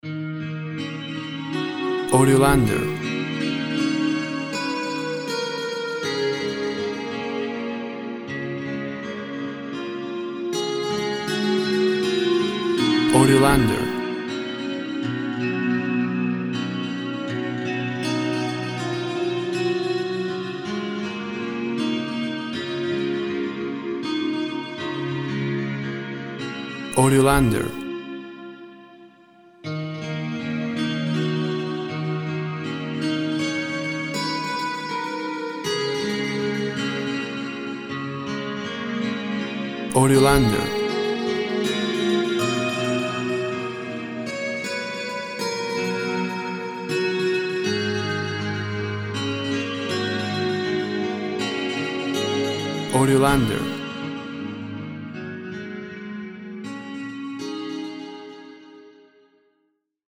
Dreamy solo guitar with heavy reverb.
WAV Sample Rate 24-Bit Stereo, 44.1 kHz
Tempo (BPM) 68